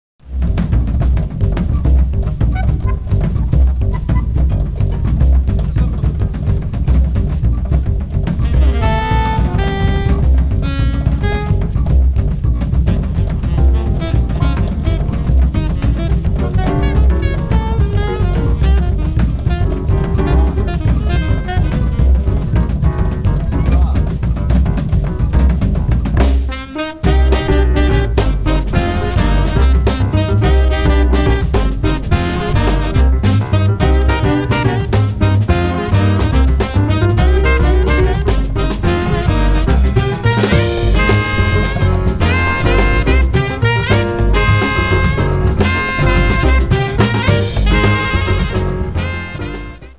alto sax
tenor sax
Un 6/8 spinto